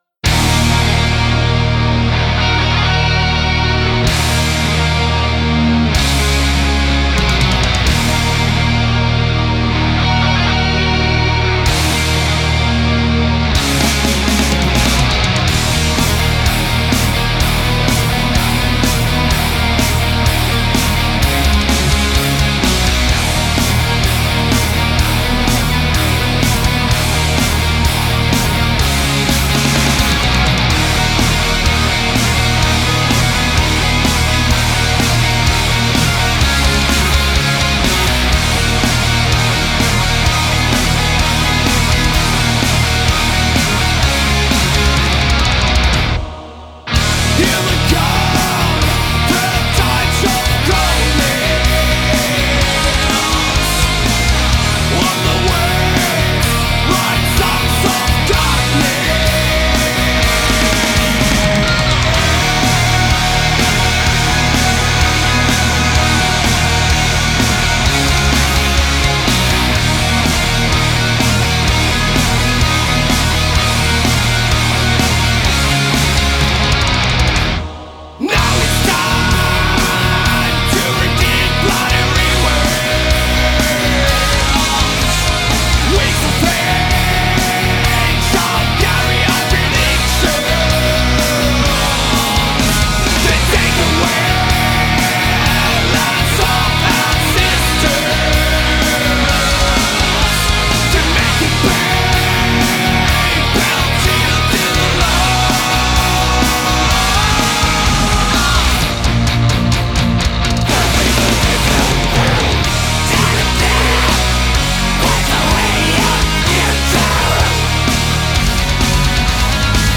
Death Metal